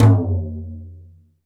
• Short Reverb Tom Drum One Shot F Key 18.wav
Royality free tom drum single hit tuned to the F note. Loudest frequency: 302Hz
short-reverb-tom-drum-one-shot-f-key-18-01M.wav